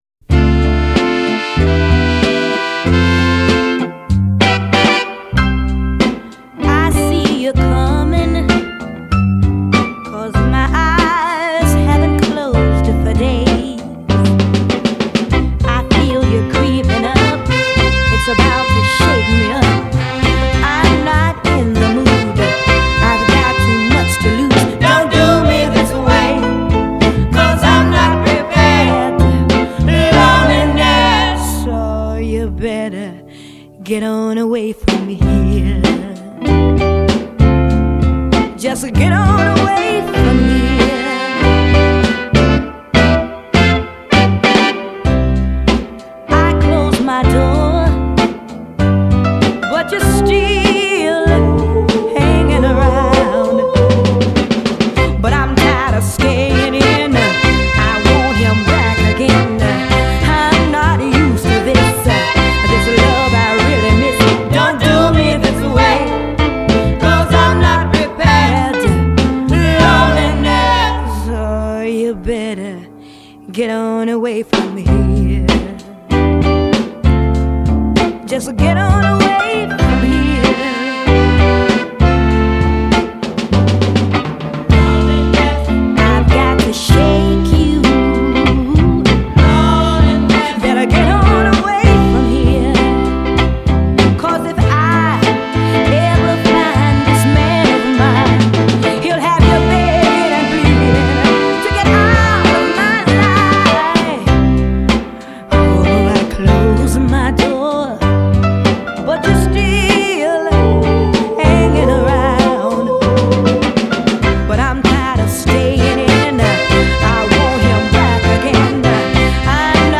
the shiny brass horns